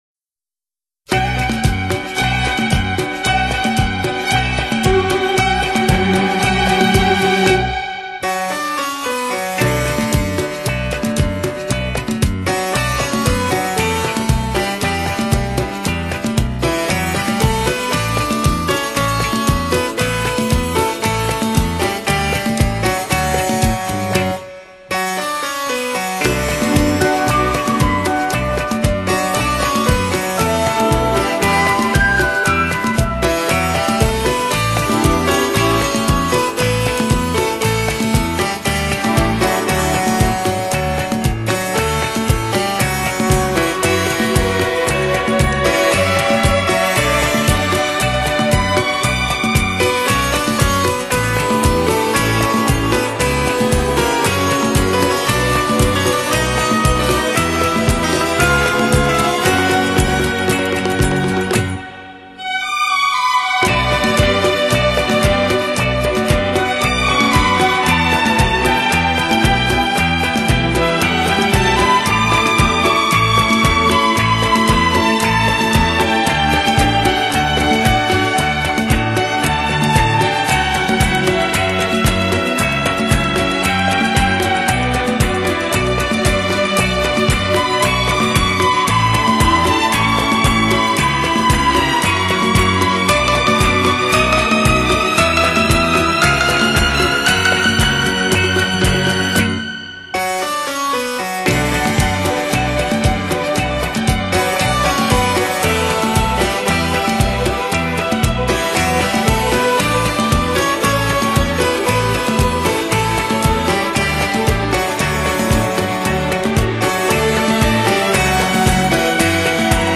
音乐类型:轻音乐